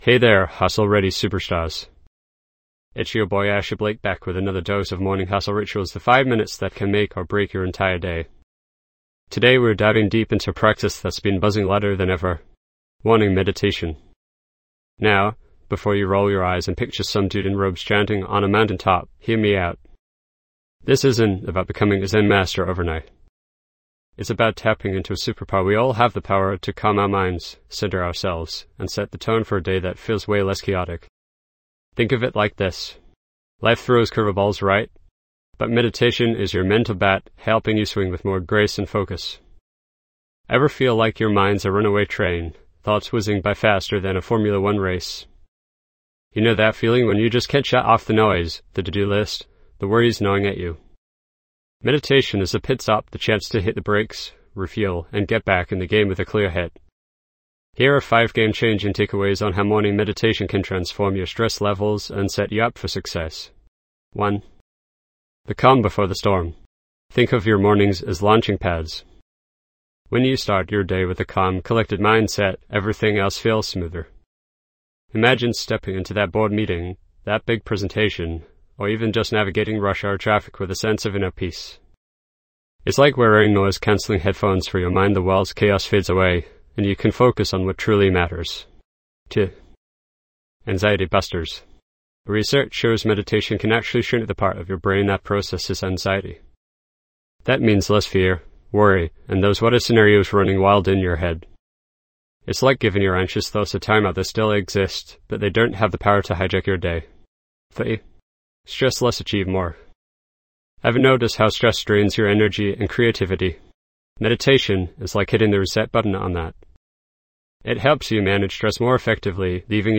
Unwind the stress of the day ahead and conquer anxiety with our expert-guided morning meditation session.
Dive into a tranquil ambiance designed to refresh your mind and rejuvenate your spirit.
This podcast is created with the help of advanced AI to deliver thoughtful affirmations and positive messages just for you.